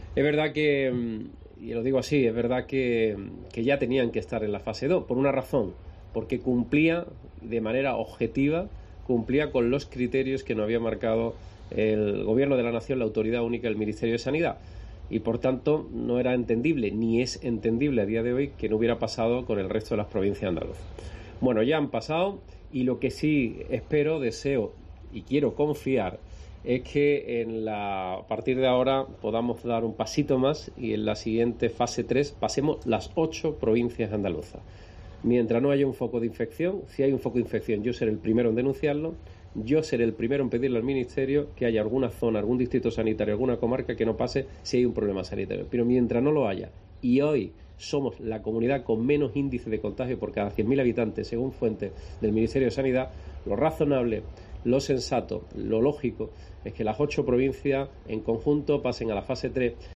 Audio del presidente de la Junta, Juanma Moreno Bonilla.